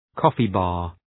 Προφορά
coffee-bar.mp3